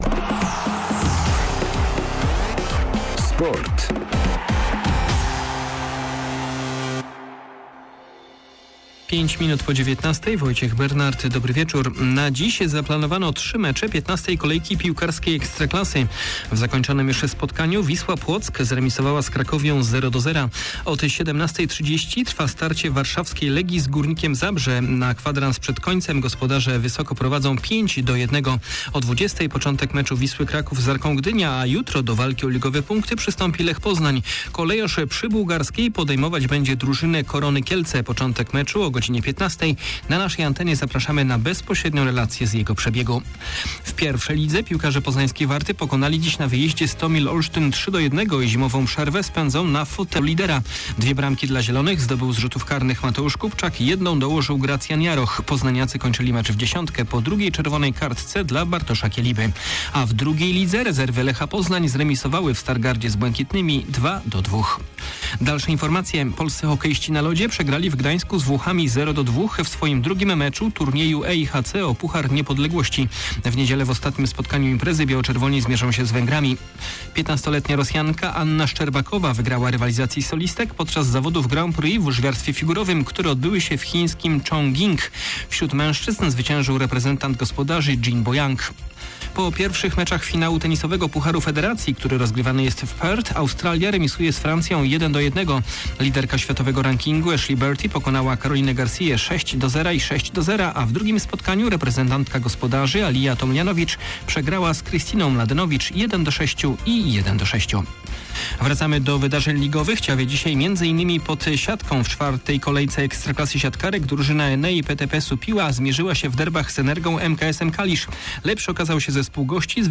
09.11. SERWIS SPORTOWY GODZ. 19:05
W wieczornym serwisie sporo o wydarzeniach ligowej soboty. W ramach omówienia występów wielkopolskich drużyn między innymi dźwiękowe komentarze po meczu koszykarek pierwszoligowego MUKS Poznań oraz po derbach w Superlidze hokeistów na trawie.